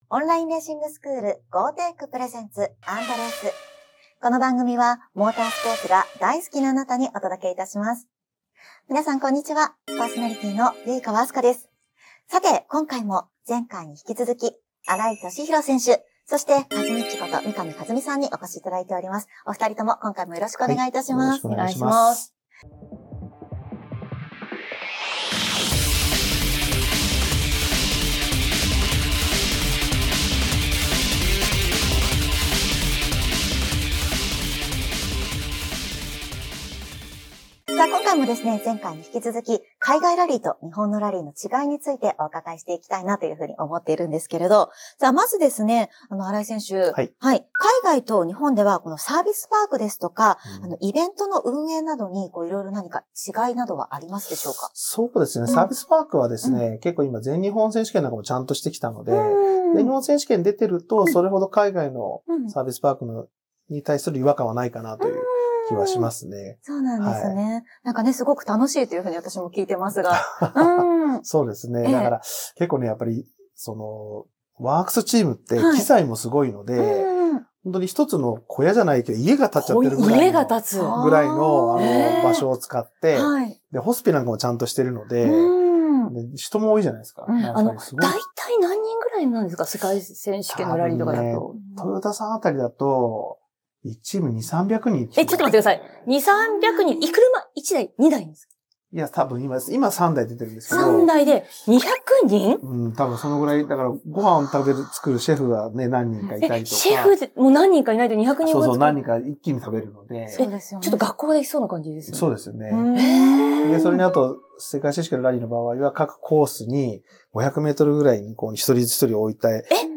今回も、世界を股にかけるラリースト・新井敏弘選手をお迎えし、海外ラリーの世界を深掘りします。ワークスチームの驚異的な規模や、リアルタイムで路面状況を伝える緻密な情報網など、WRCの知られざる舞台裏を明かしていただきます。
さらに、「世界に挑む」ために必要なマインドセットや具体的なアドバイスを新井選手が熱く語ります。